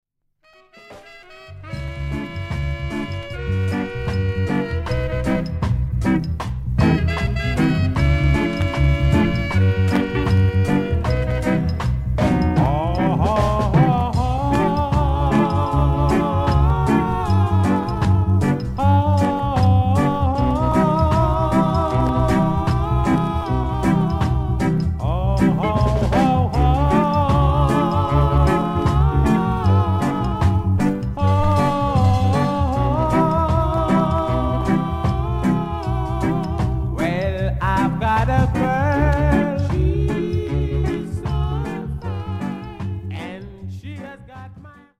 girly backing vocals nice sax and bit more bassy